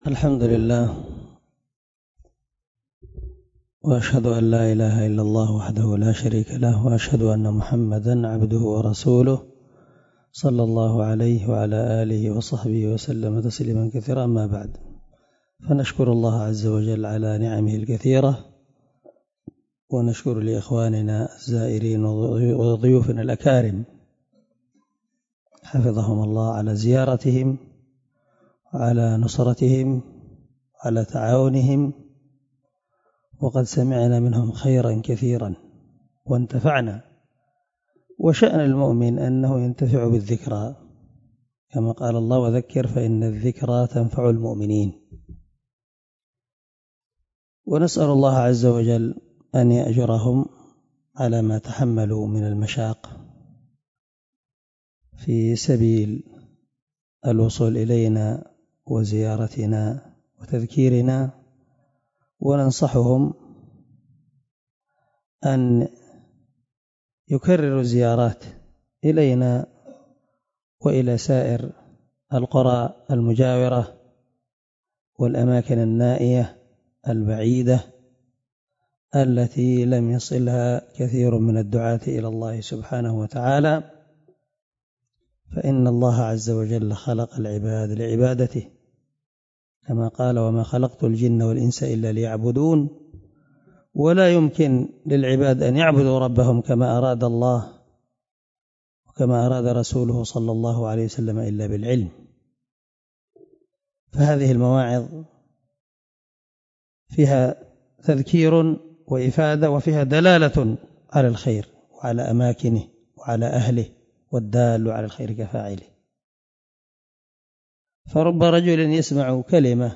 كلمة شكر
(التي بكى فيها الشيخ)